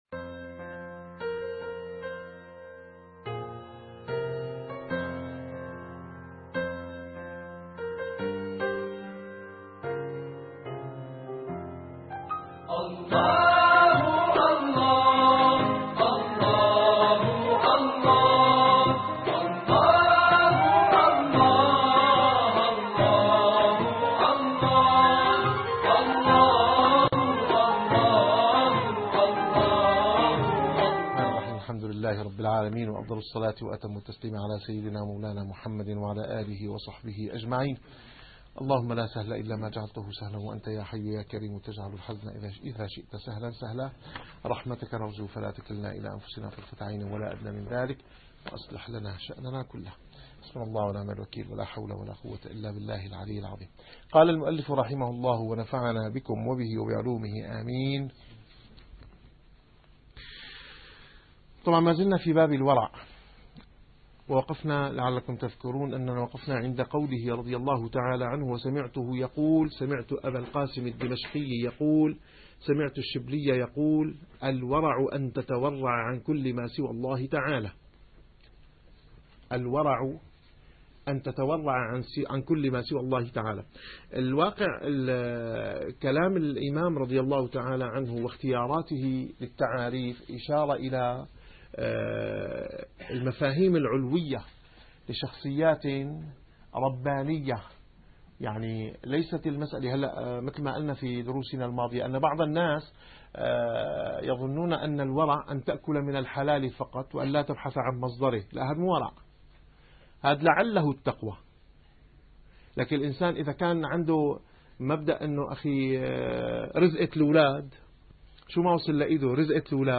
- الدروس العلمية - الرسالة القشيرية - الرسالة القشيرية / الدرس التاسع والخمسون.